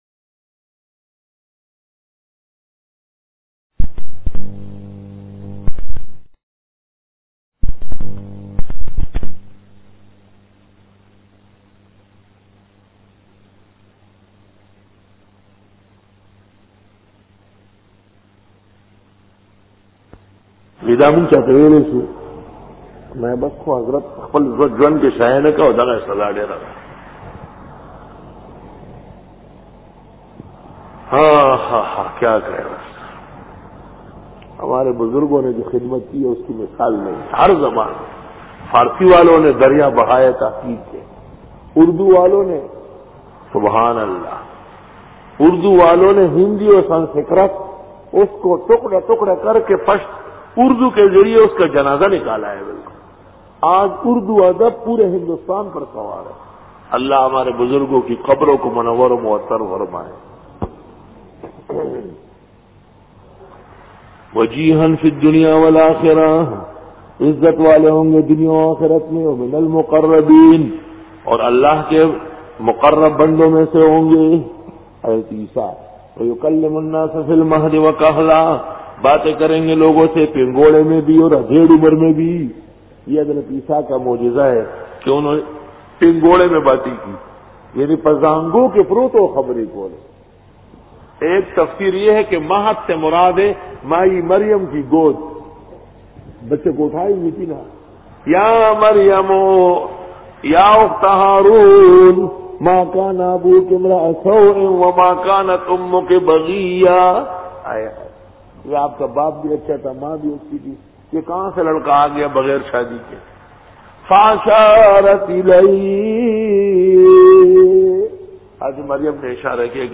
Dora-e-Tafseer 2000